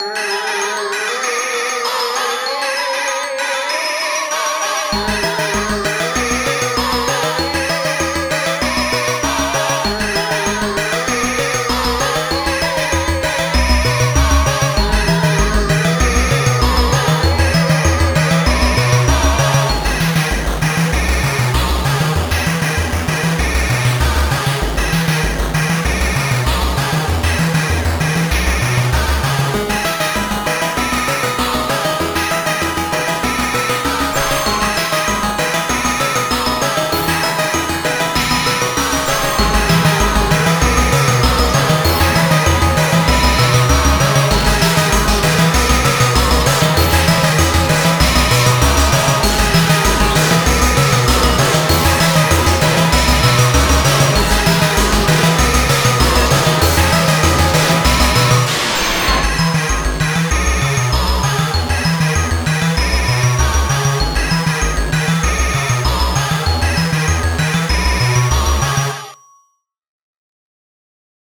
breakcore, glitch, electronica,